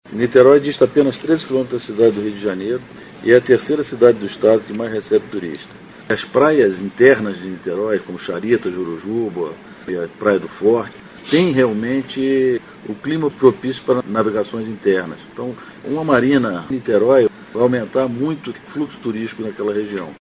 aqui e ouça declaração do secretário Sérgio Braune sobre a importância da iniciativa para o turismo náutico na região.